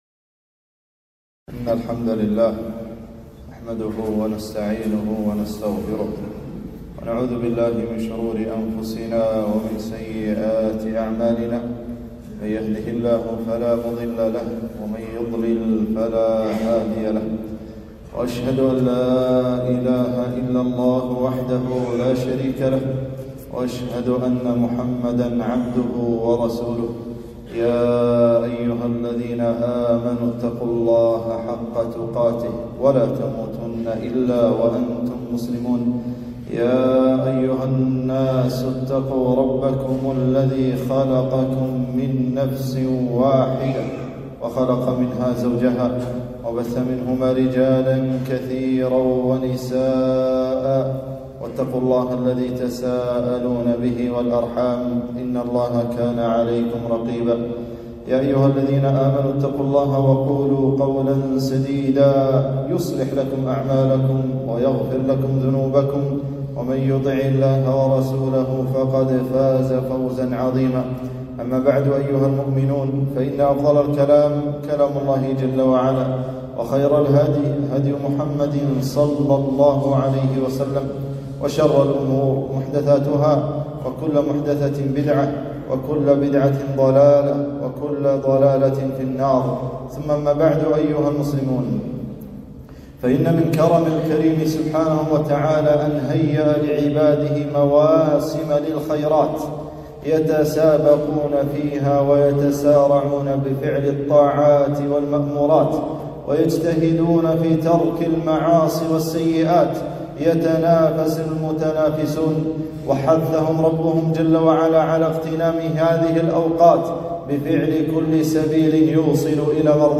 خطبة - فضل عشر ذي الحجة